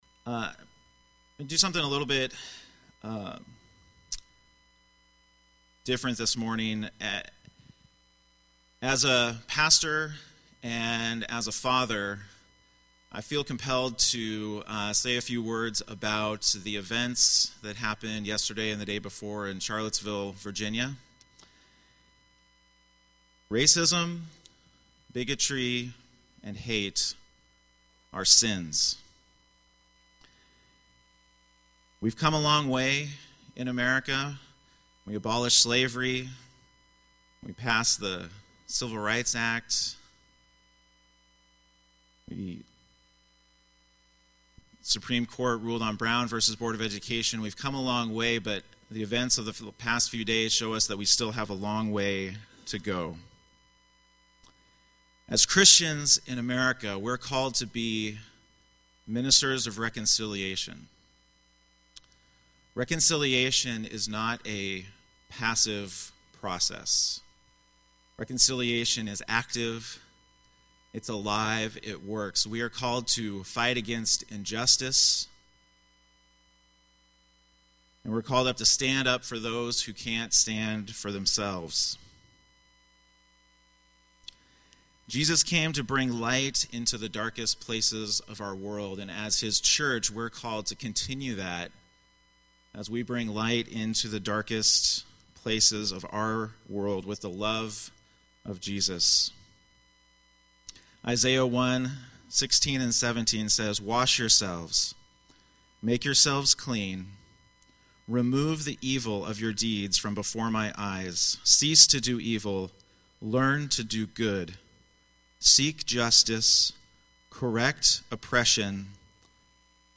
Post-Charlottesville Sermon, Yakima Valley Church